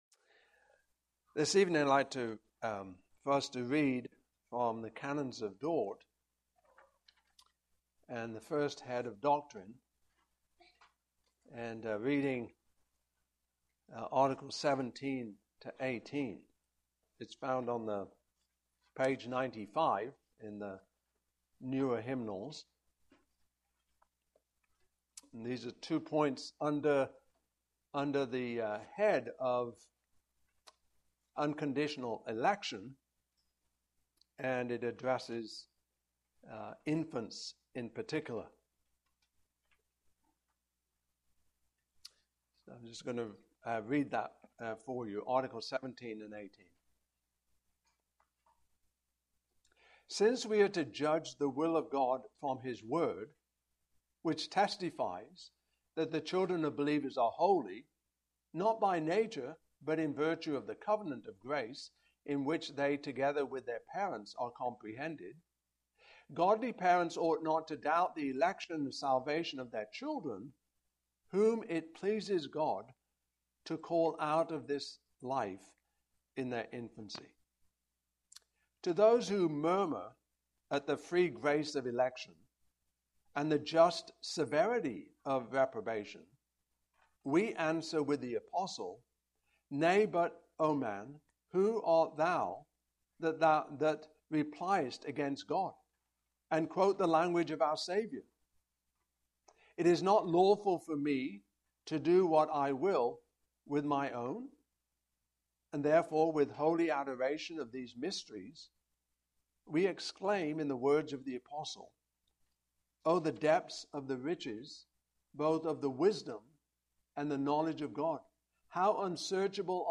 Genesis 25-19-28 Service Type: Evening Service « Who is Jesus?